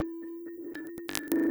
Abstract Rhythm 23.wav